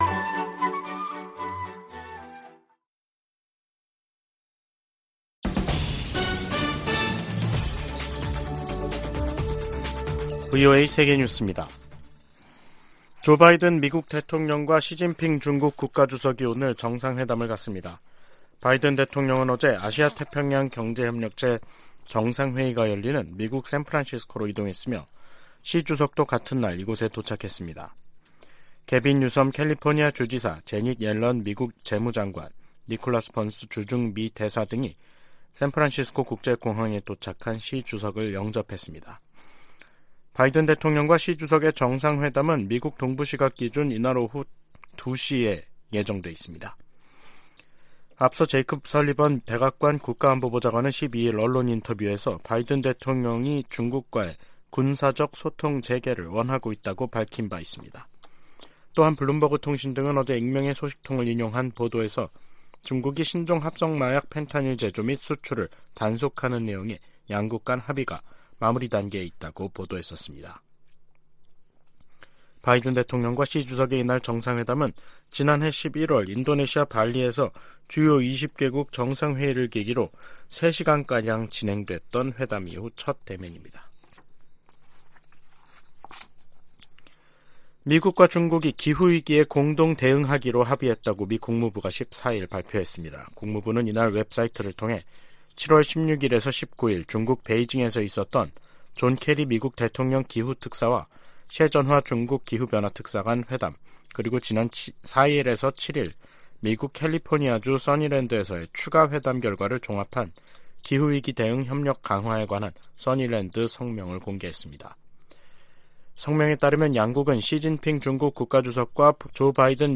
VOA 한국어 간판 뉴스 프로그램 '뉴스 투데이', 2023년 11월 15일 2부 방송입니다. 미국과 한국, 일본의 외교수장들이 미국에서 만나 중동 정세, 북한의 러시아 지원, 경제 협력 확대 등을 논의했습니다. 백악관은 조 바이든 대통령이 미중 정상회담과 관련해 대결과 외교 모두 두려워하지 않을 것이라고 밝혔습니다. 북한은 신형 중거리 탄도미사일, IRBM에 사용할 고체연료 엔진 시험을 성공적으로 진행했다고 밝혔습니다.